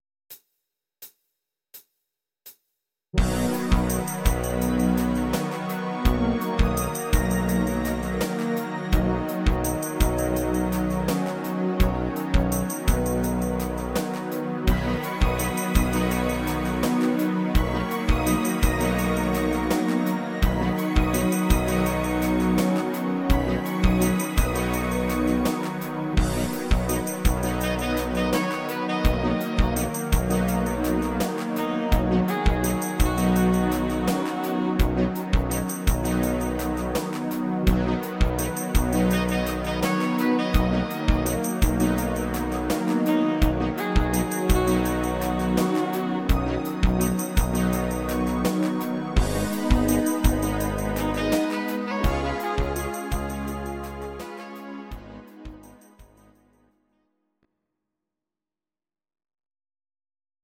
Audio Recordings based on Midi-files
Pop, Rock, 1980s